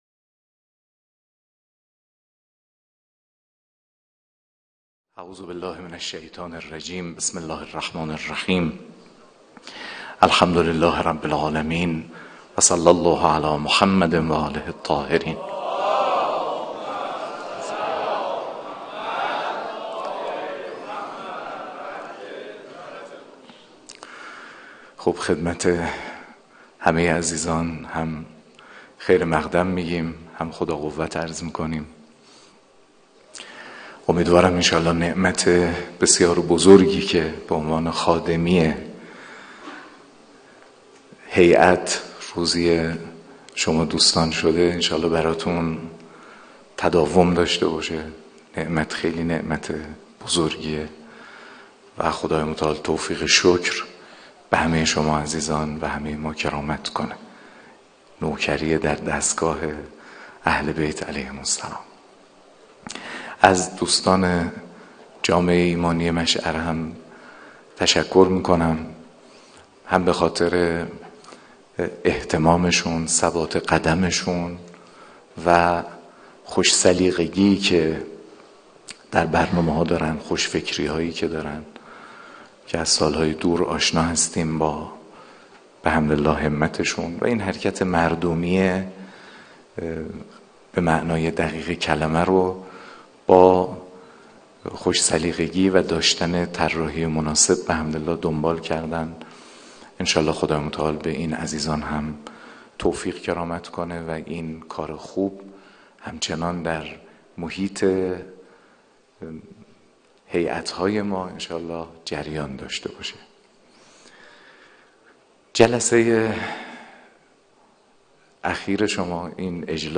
حجت الاسلام محمدجواد حاج‌علی‌اکبری؛ تربیت حماسی در مکتب فاطمی | هجدهمین همایش مدیران هیأت‌های محوری و برگزیده کشور | آبان ماه 1402 - تهران، مجموعه فرهنگی شهدای انقلاب اسلامی (سرچشمه) | جامعه ایمانی مشعر